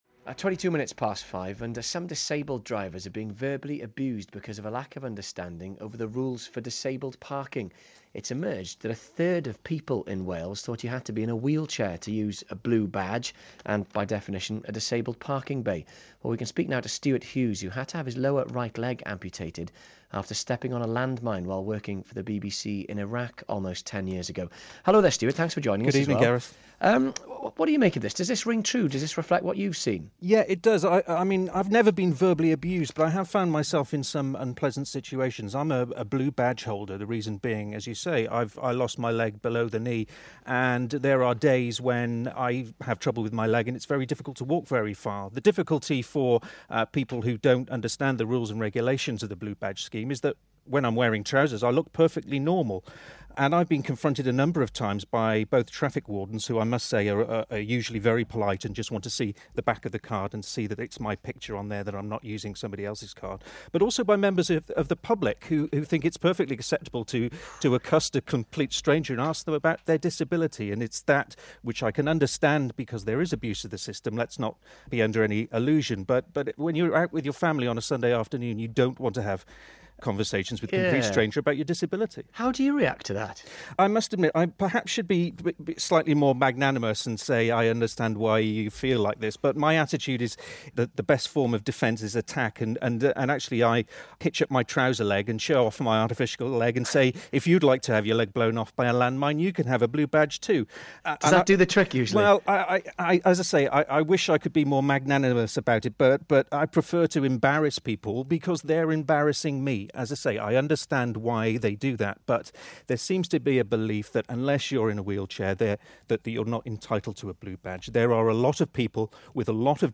Broadcast on BBC Radio Wales "Good Evening Wales" Monday 26th November 2012